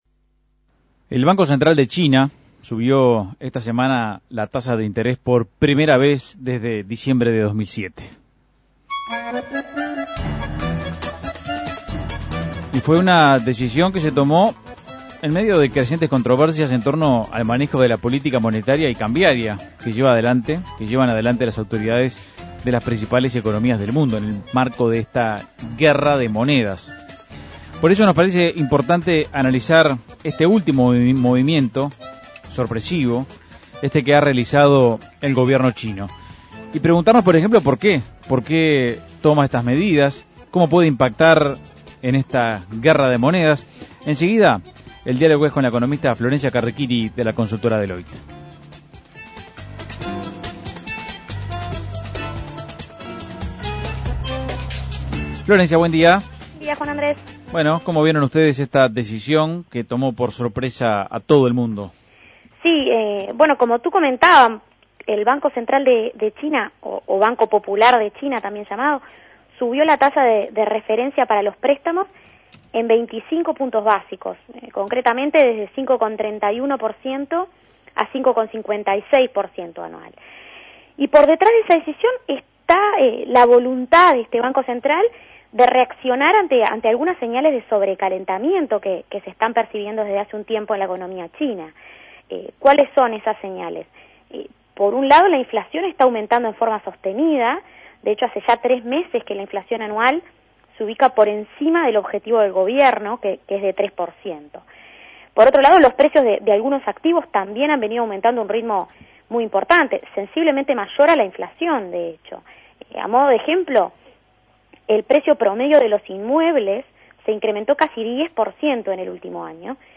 Análisis Económico El banco central de China subió la tasa de interés: ¿cómo puede impactar esta medida en la llamada "guerra de monedas"?